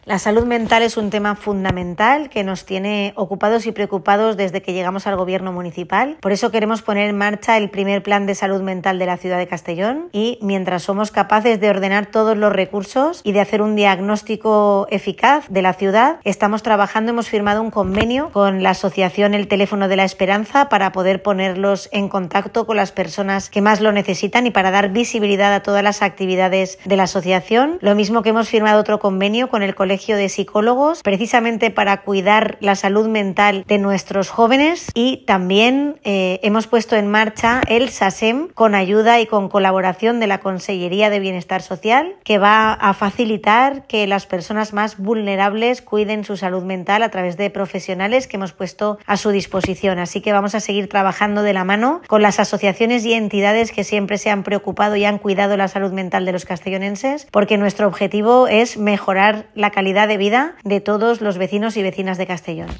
Begoña Carrasco ha dado todos los detalles en el marco del acto de conmemoración del Día Mundial de la Salud Mental, bajo el lema «Compartimos vulnerabilidad, defendemos nuestra salud mental».
Corte de voz de la alcaldesa de Castellón, Begoña Carrasco.